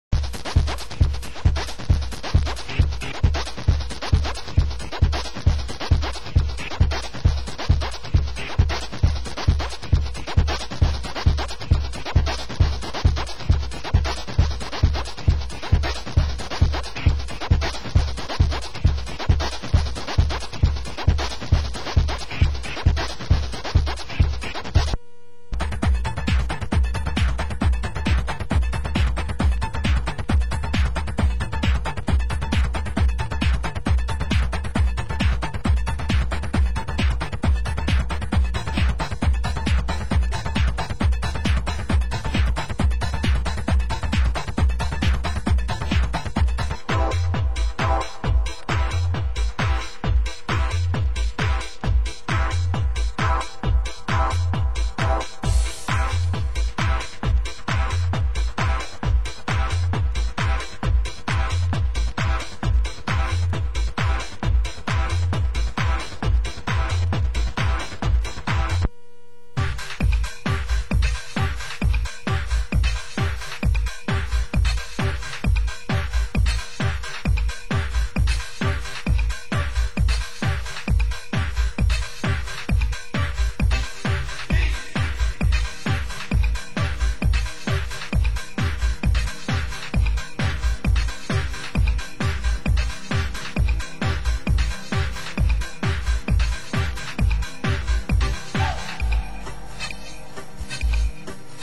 Format: Vinyl 12 Inch
Genre: Tech House